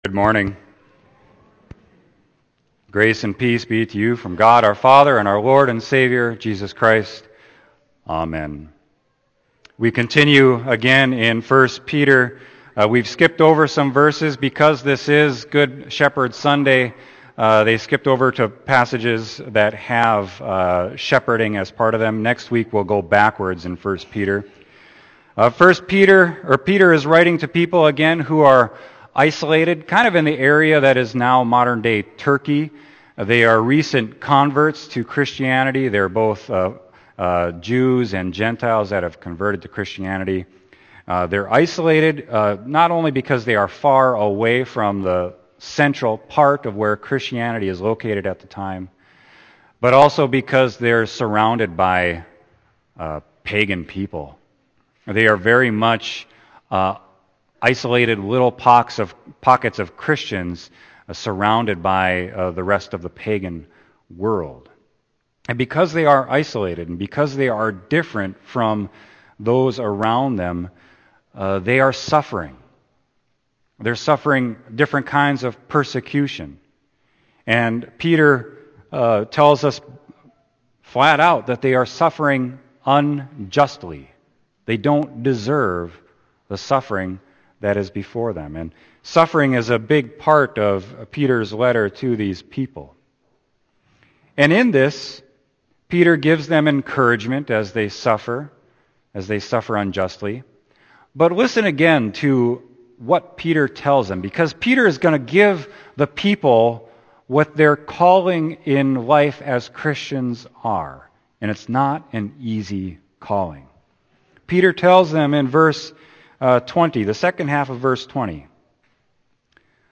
Sermon: 1 Peter 2.19-25